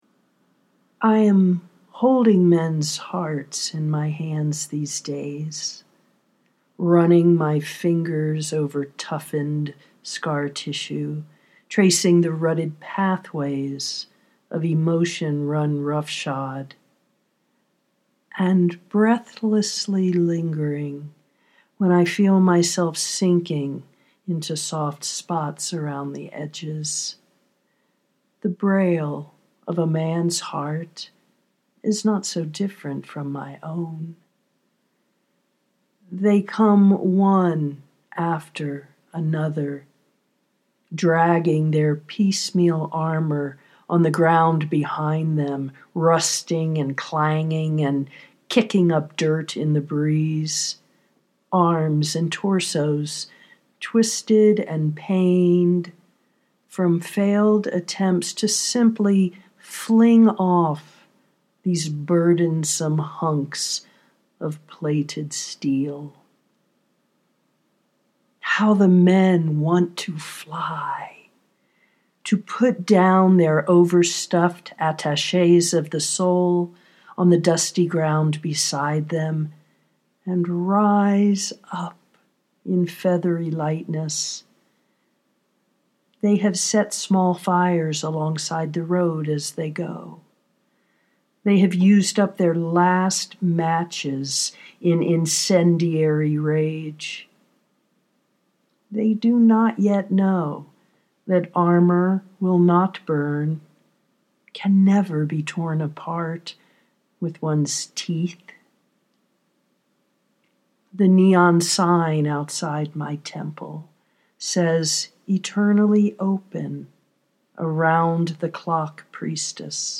this one’s for the boys: how the men want to fly! (audio poetry 3:13)